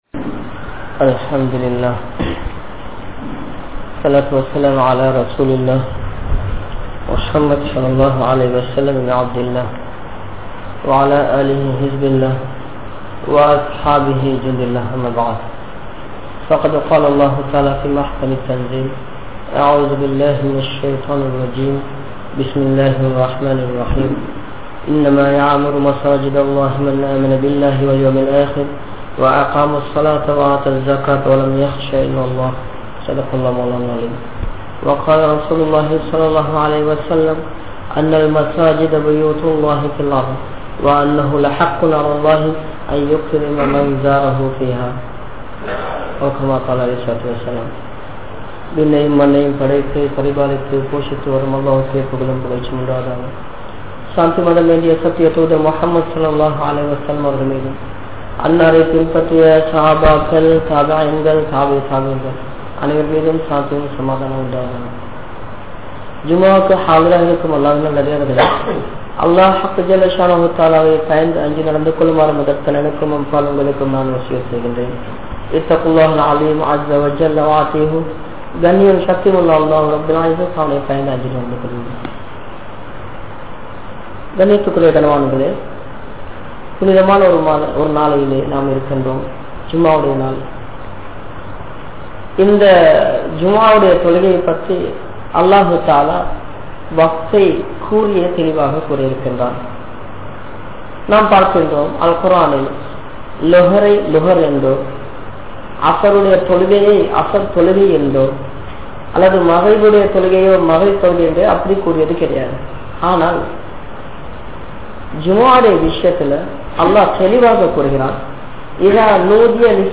Niruvaahihalin Kadamaihal | Audio Bayans | All Ceylon Muslim Youth Community | Addalaichenai
Kandy, DanGolla Jumua Masjith